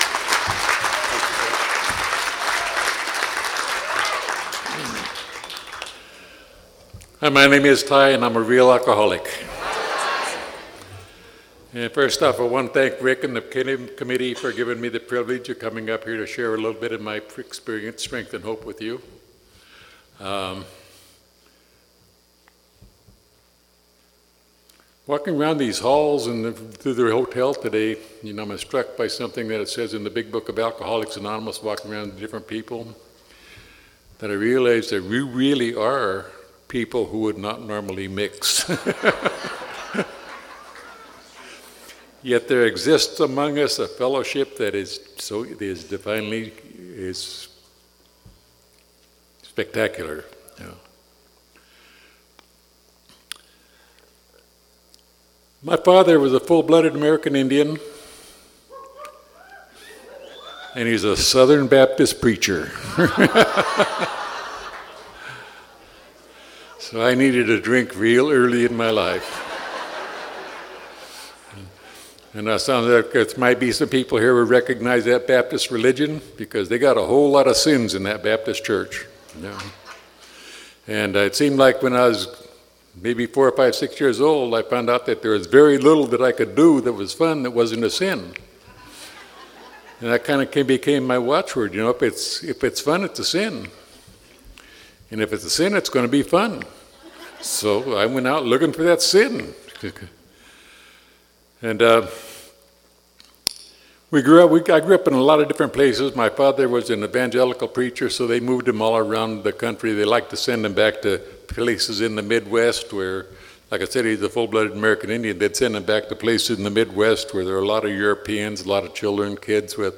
49th San Fernando Valley Alcoholics Anonymous Convention